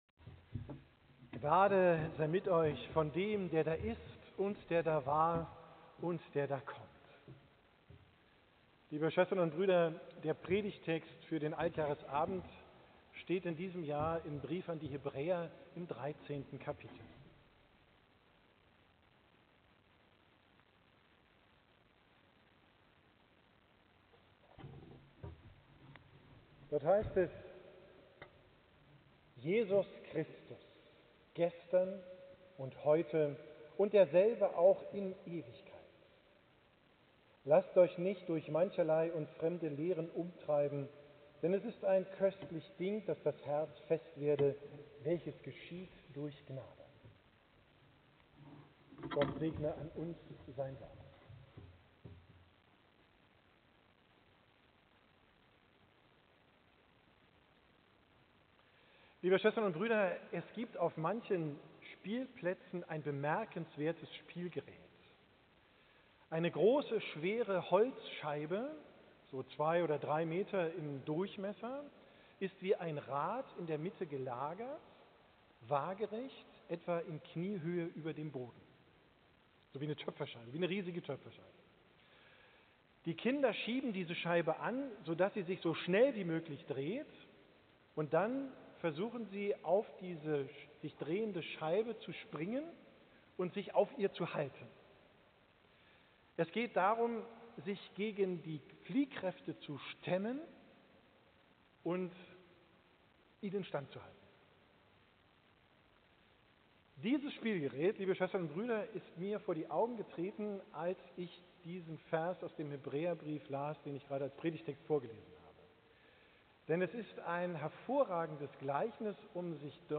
Predigt vom Altjahresabend 2025